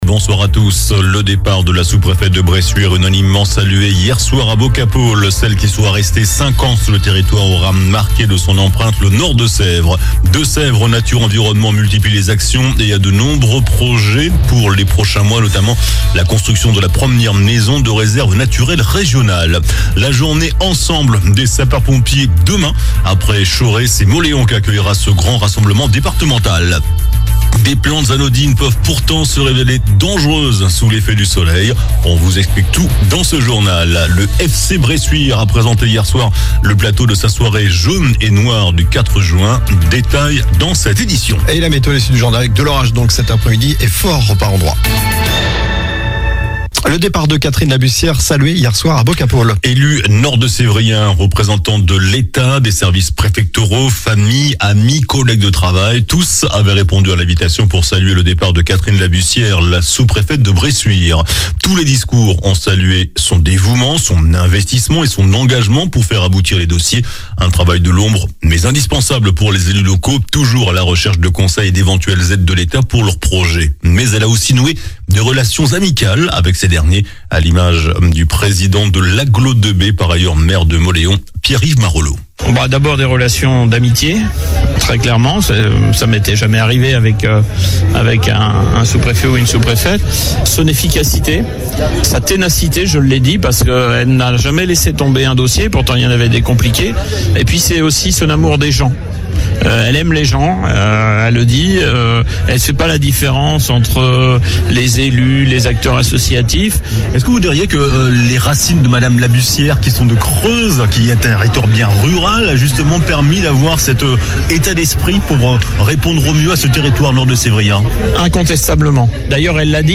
JOURNAL DU VENDREDI 03 MAI ( SOIR )